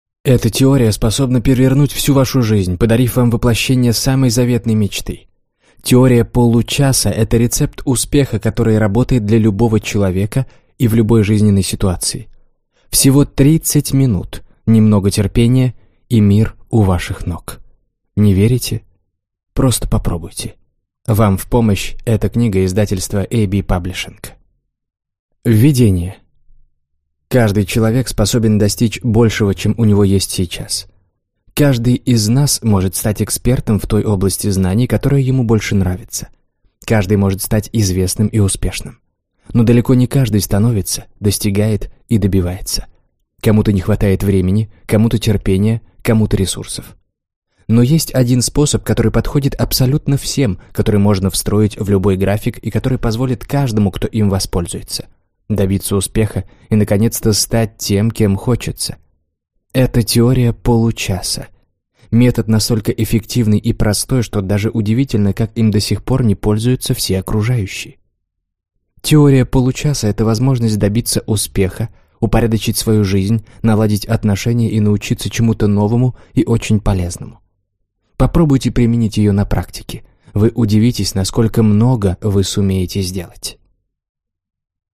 Аудиокнига Теория получаса. Как изменить жизнь за 30 минут в день | Библиотека аудиокниг